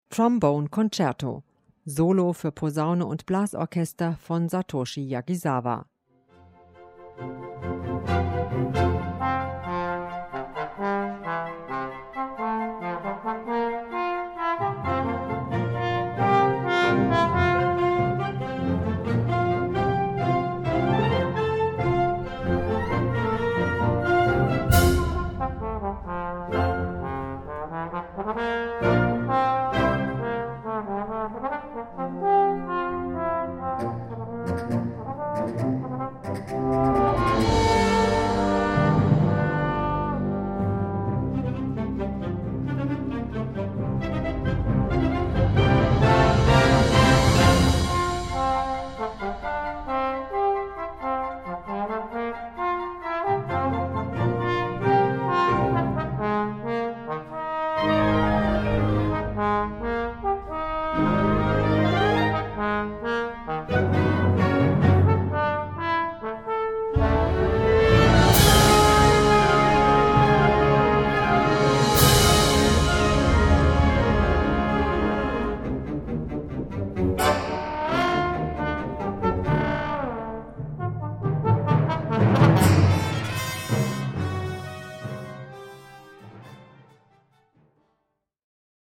Gattung: Solo für Posaune und Blasorchester
Besetzung: Blasorchester